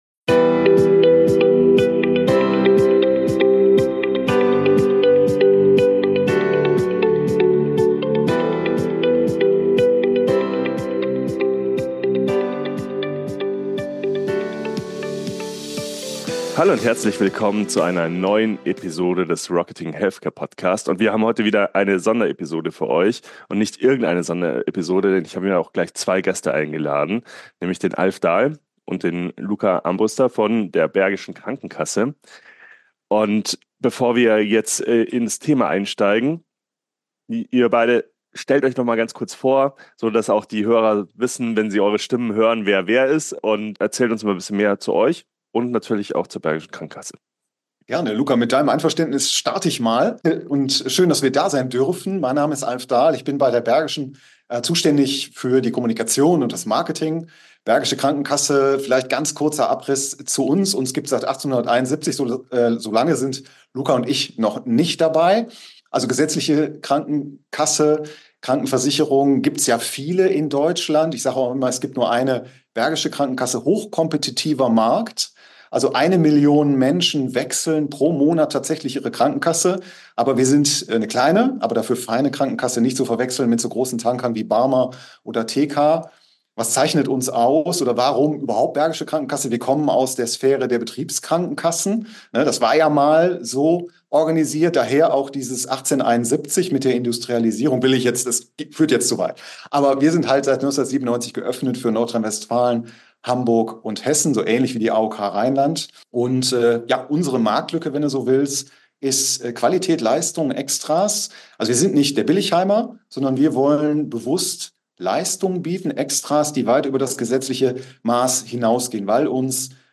Unsere Interview Gäste